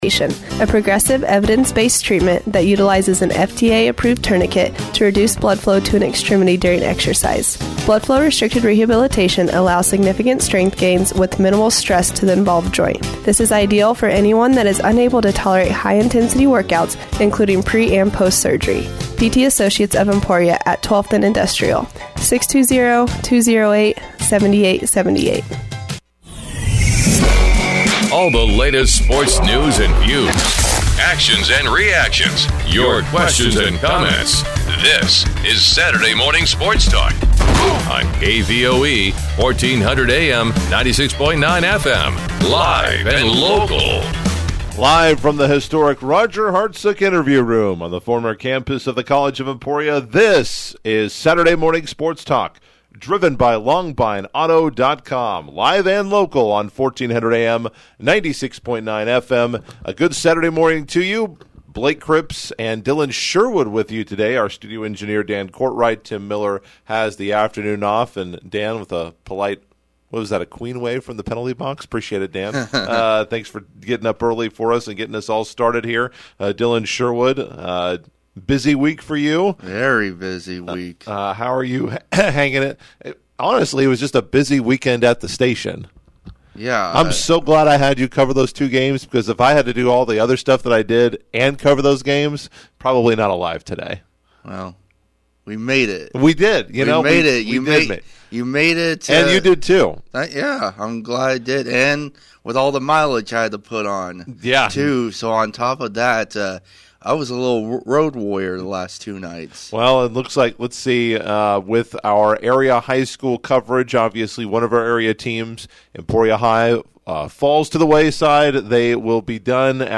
saturday-morning-sports-talk-10-28.mp3